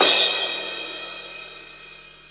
CRASH05   -L.wav